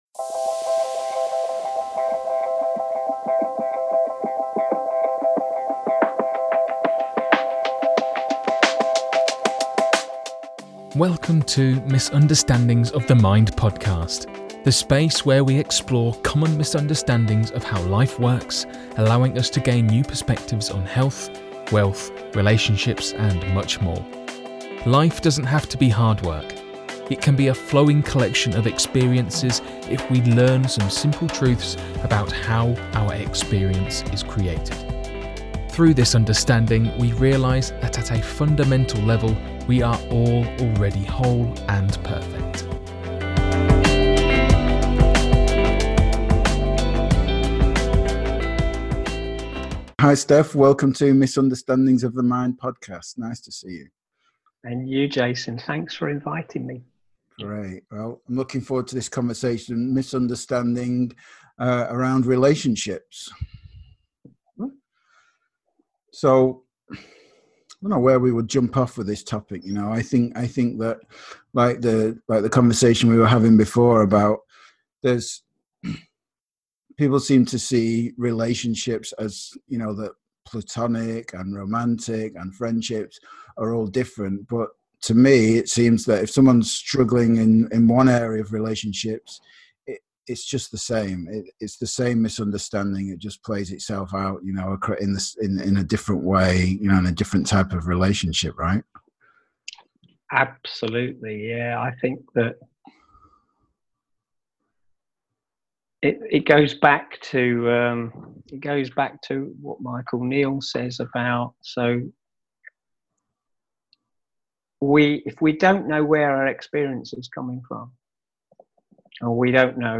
A great conversation